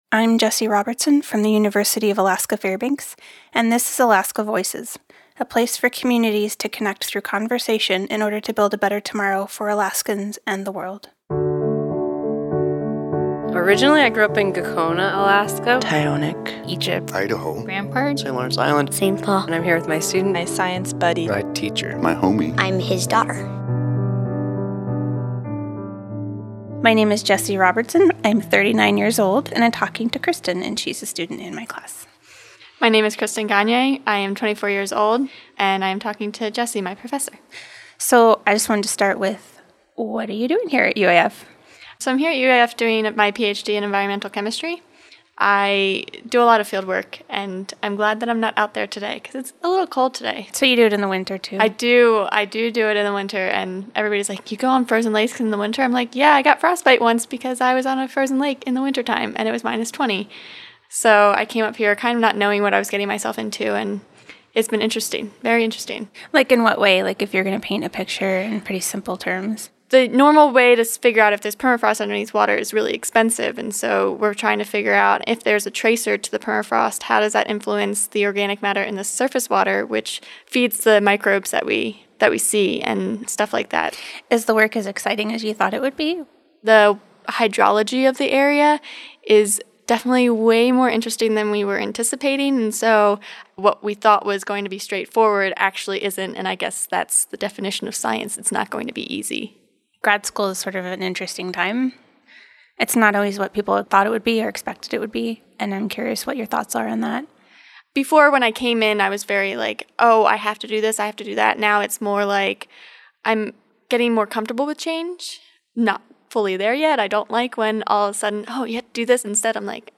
Content warning: This episode includes some adult language.
This interview was recorded in collaboration with StoryCorps.